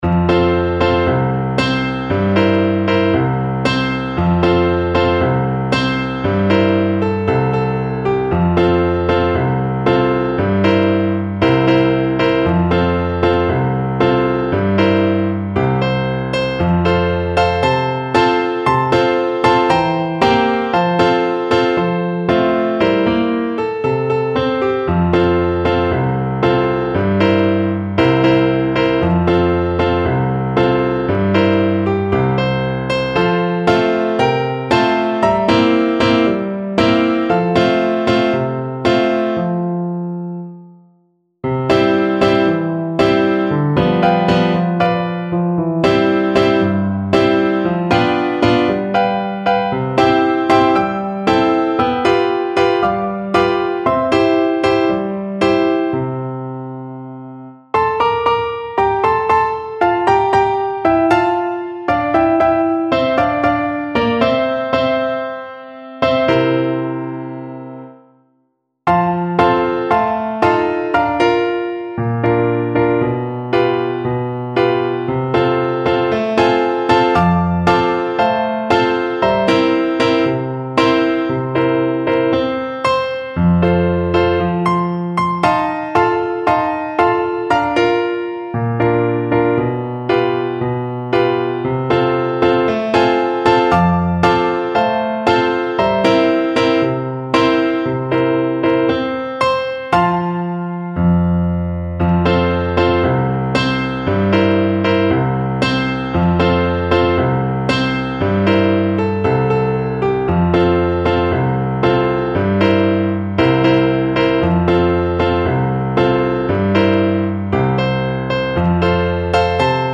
2/4 (View more 2/4 Music)
F3-D5
World (View more World Cello Music)
Brazilian Choro for Cello